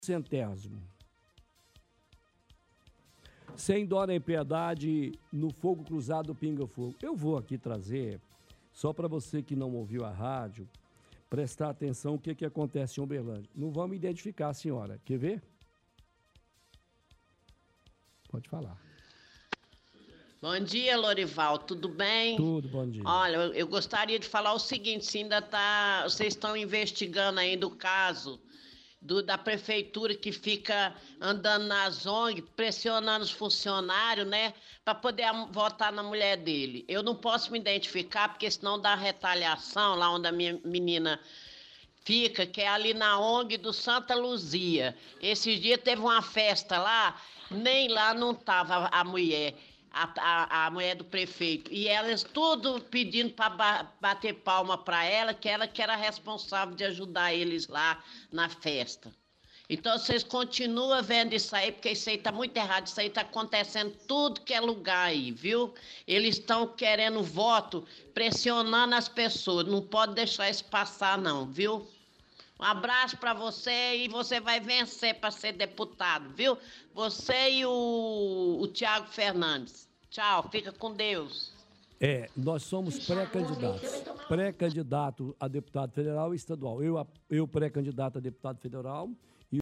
– Ouvinte pergunta se a Vitoriosa ainda está investigando sobre o caso que a prefeitura fica andando nas Ogns pressionando funcionários a votar na mulher do prefeito.